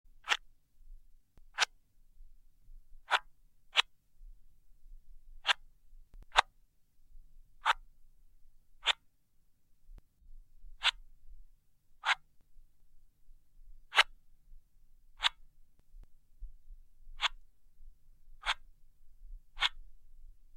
Tiếng Vuốt Màn Hình Cảm ứng, xem TikTok, sử dụng điện thoại… (Sample 2)
Thể loại: Tiếng đồ công nghệ
Description: Khi vuốt màn hình cảm ứng trên điện thoại để xem TikTok hay chỉ đơn giản là sử dụng điện thoại hàng ngày, bạn sẽ cảm nhận được âm thanh nhẹ nhàng, như một hiệu ứng âm thanh như thể đang tạo ra một bản nhạc riêng cho chính mình.
tieng-vuot-man-hinh-cam-ung-xem-tiktok-su-dung-dien-thoai-sample-2-www_tiengdong_com.mp3